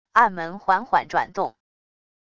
暗门缓缓转动wav音频